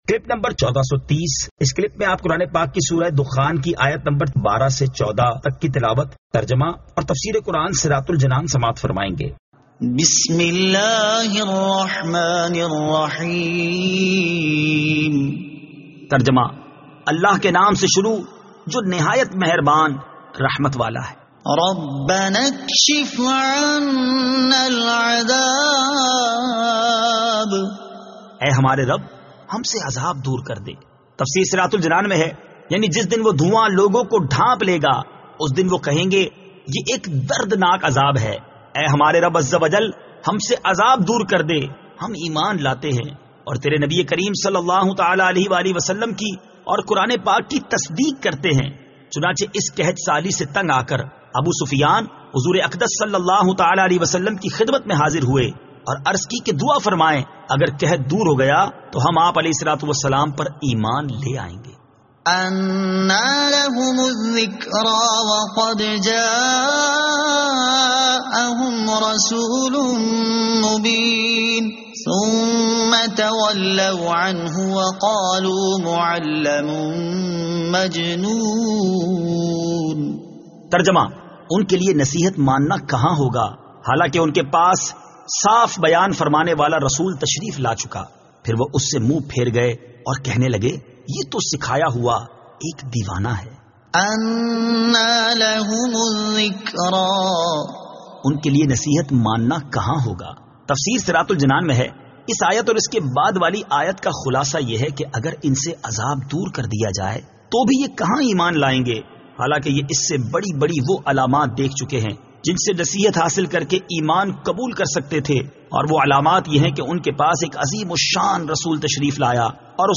Surah Ad-Dukhan 12 To 14 Tilawat , Tarjama , Tafseer